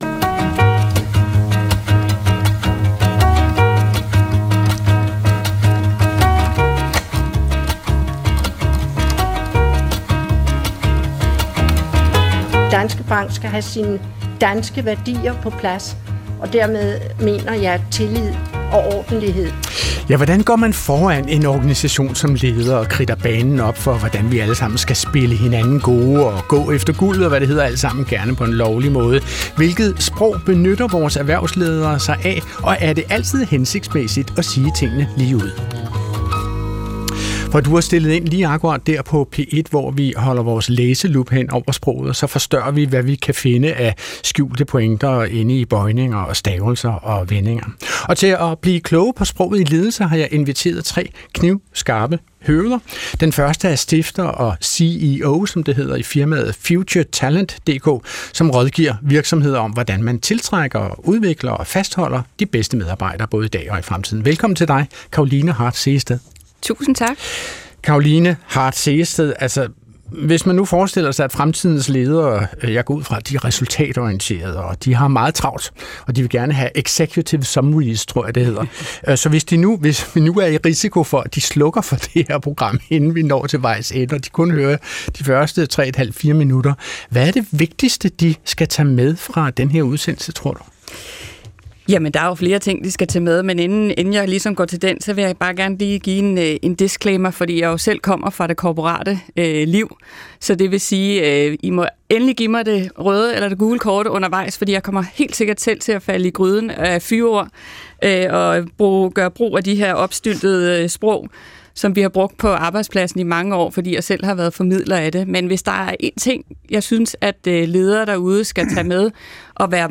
Klog på Sprog er programmet, der interesserer sig for, leger med og endevender det sprog, vi alle sammen taler til daglig. Adrian Hughes er værten, der sammen med et veloplagt panel er helt vild med dansk.